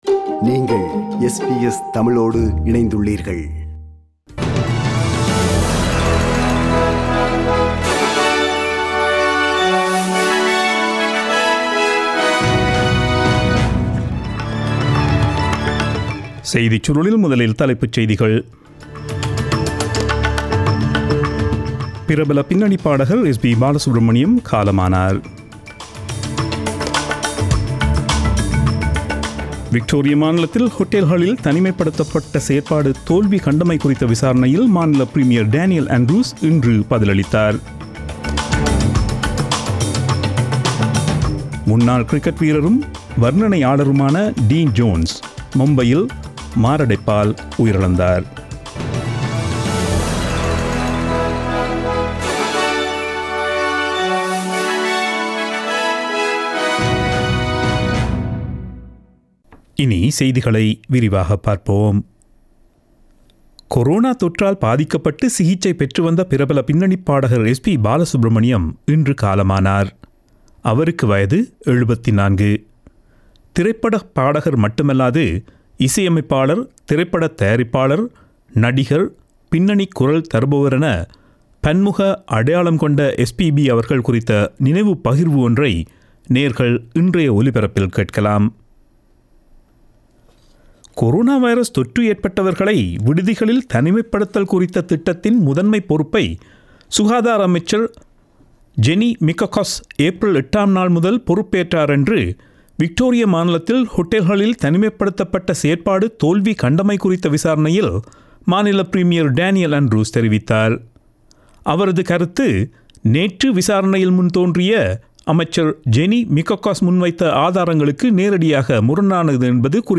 Australian news bulletin aired on Friday 25 September 2020 at 8pm.